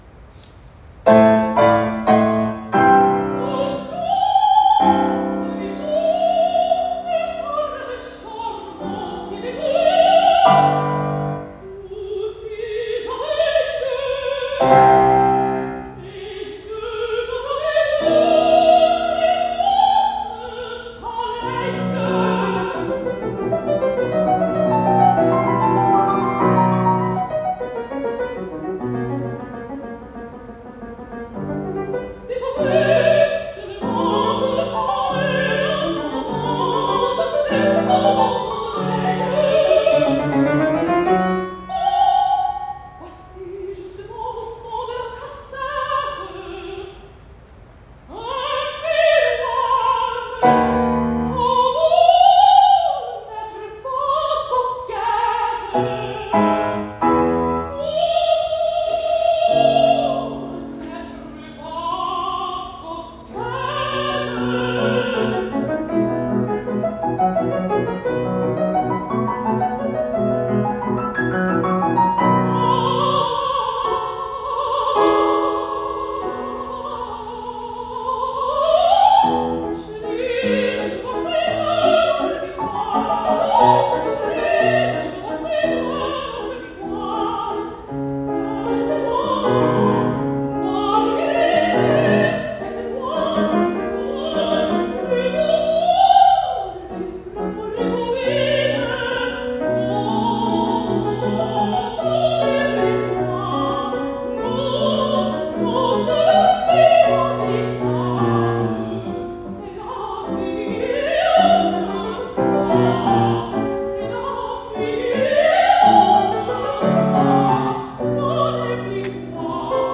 SOPRANO